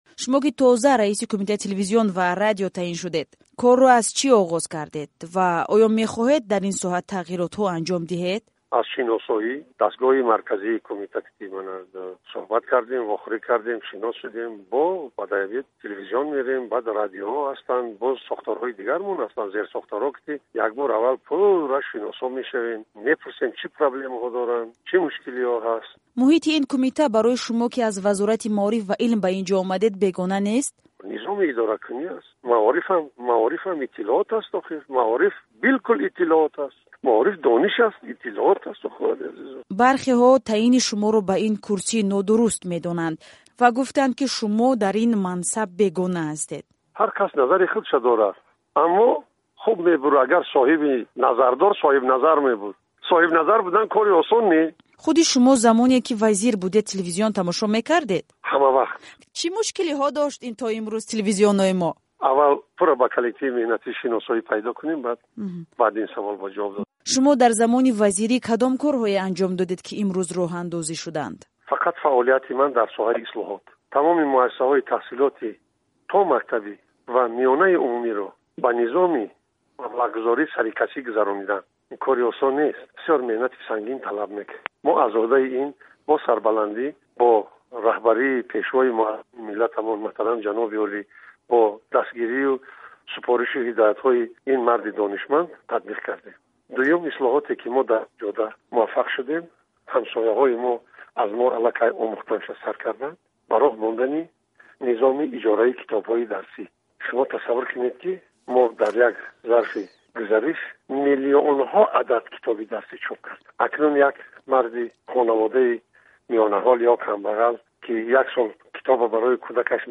Хабарнигори Радиои Озодӣ рӯзи 27-уми январ дар суҳбати кӯтоҳи телефонӣ аз Нуриддин Саид пурсид, ки ба ин назарҳо чӣ мегӯяд?